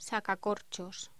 Locución: Sacacorchos
voz
Sonidos: Voz humana